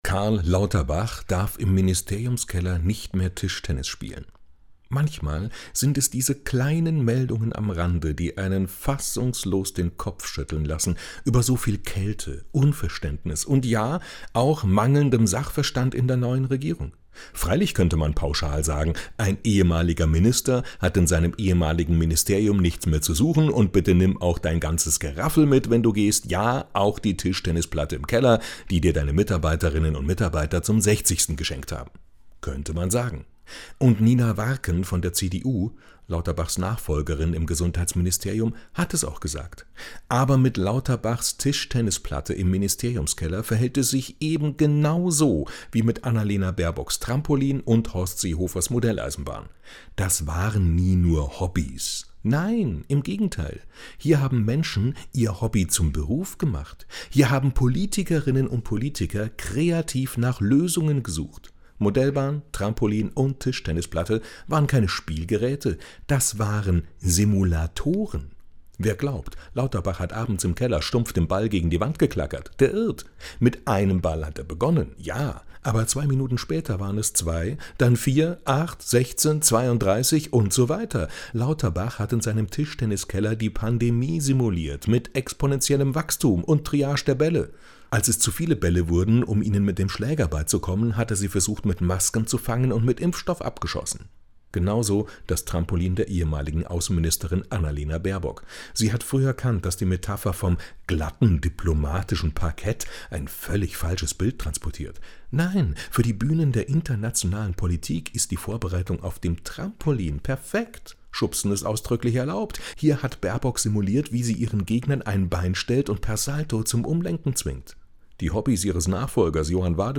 tägliche Glosse von wechselnden Autor*innen, Bayern 2 Radiowelt